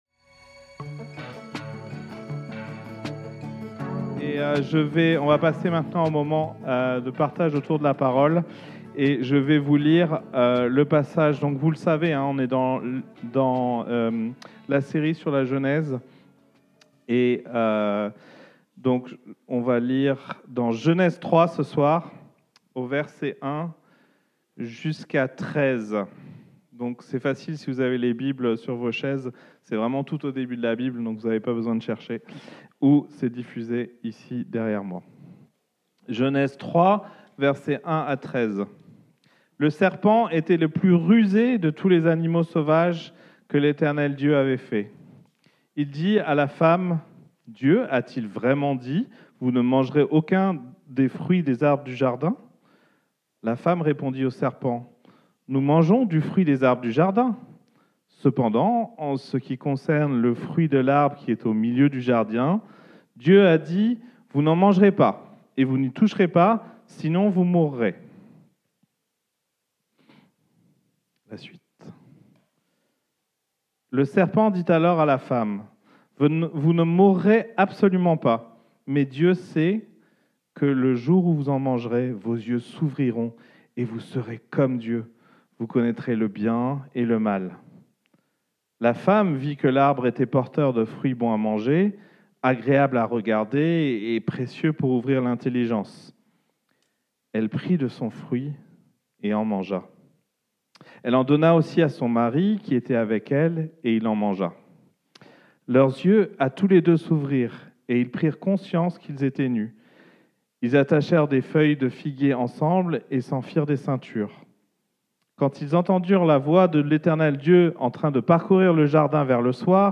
Predication-3-30.mp3